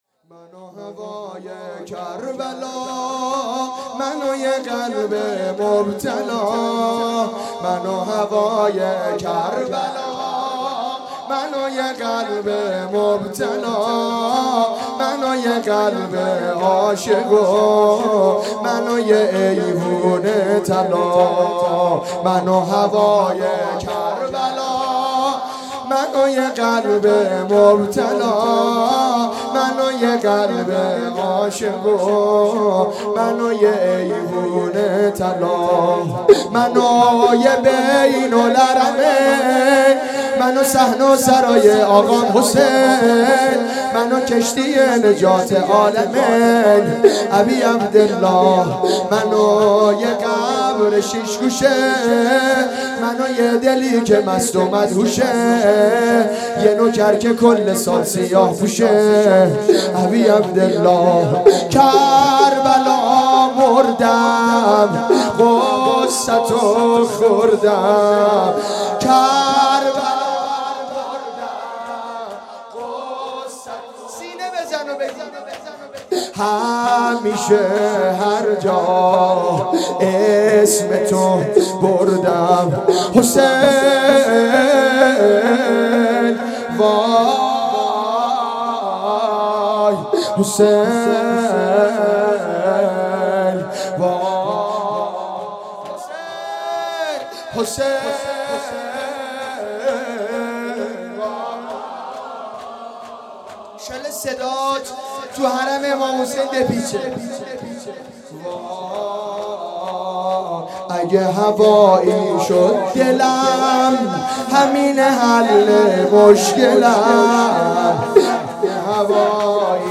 اقامه عزای شهادت امام حسن مجتبی علیه السلام